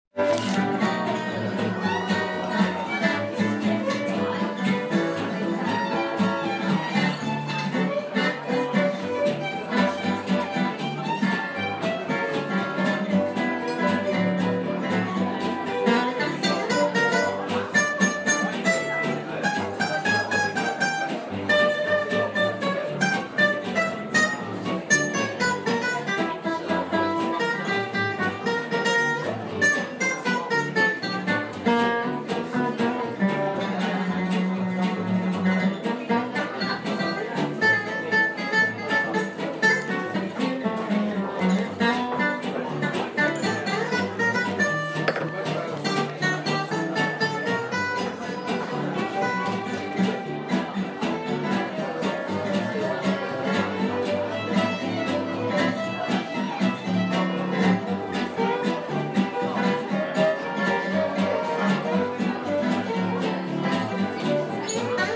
Gypsy Swing at the Pelham Arms Lewes